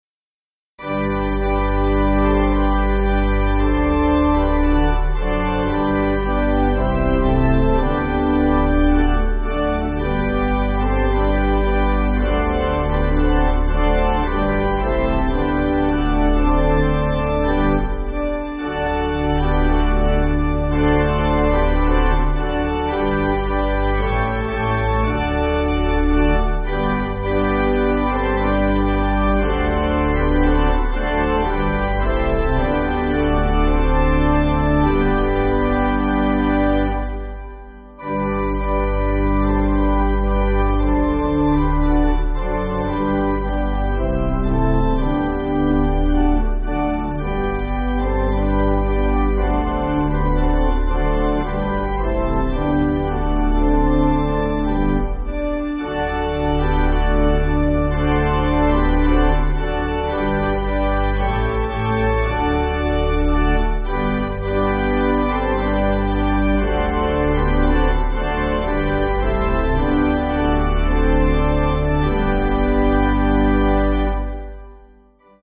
Organ
(CM)   4/G